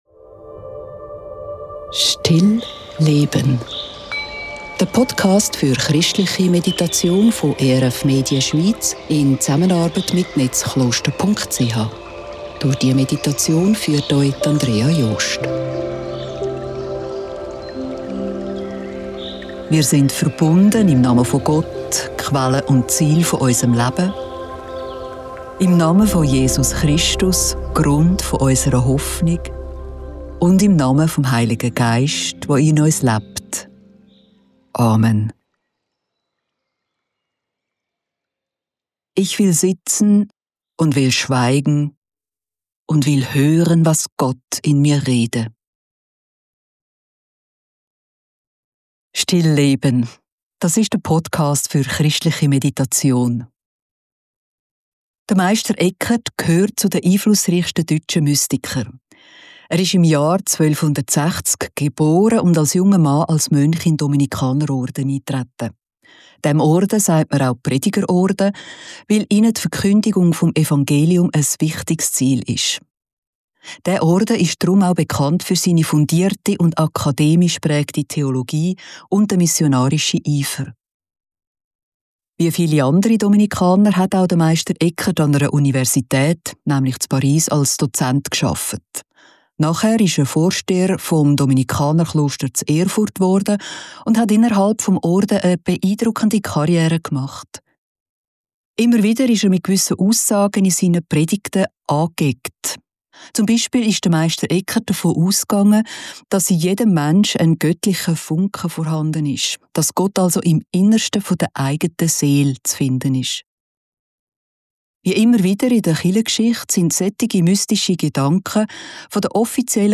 Ich will schweigen und will hören, was Gott in mir rede ~ still.leben – der Podcast für christliche Meditation Podcast